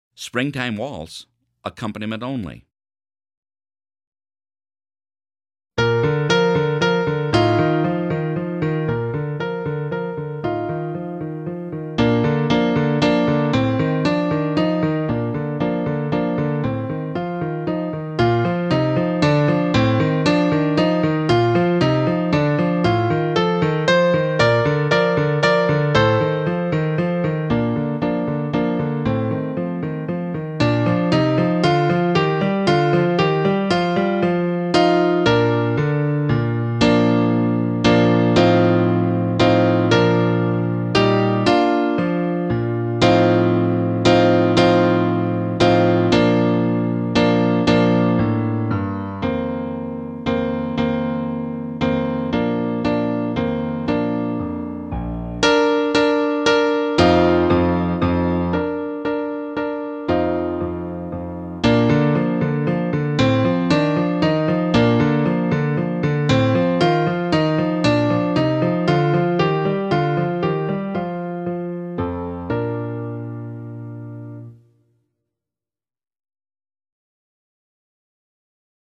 Piano Only – Performance Tempo
Springtime-Waltz-Accomp-only.mp3